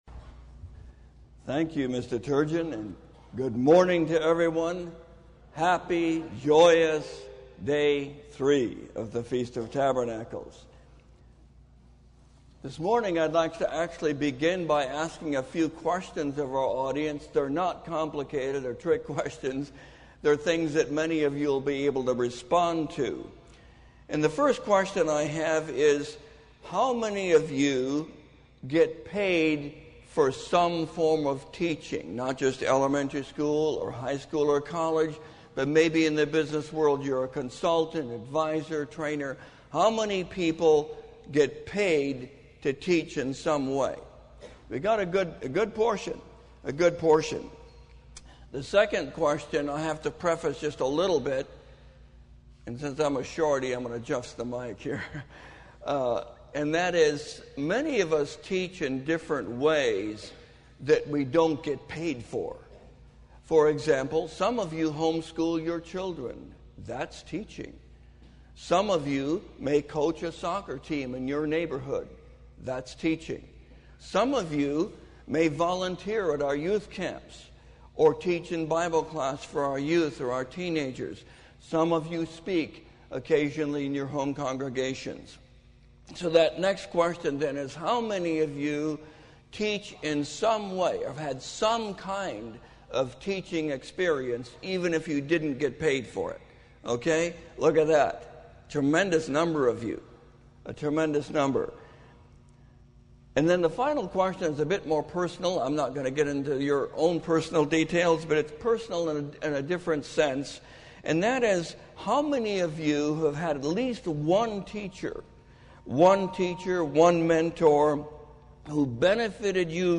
This sermon was given at the Oceanside, California 2018 Feast site.